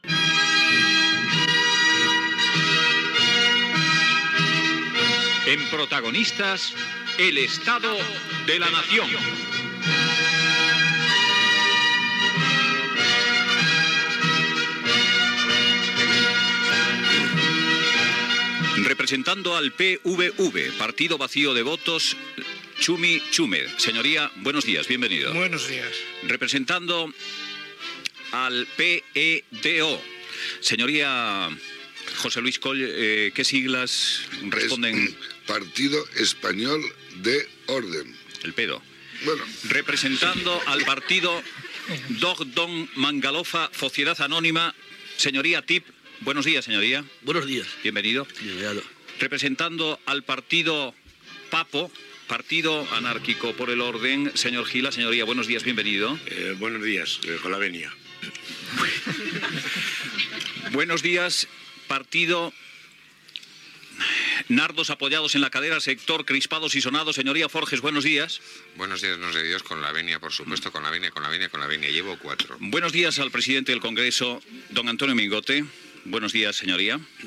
Inici de la secció humorística "El estado de la nación" amb la presentació dels representants dels diferents partíts polítics.
Entreteniment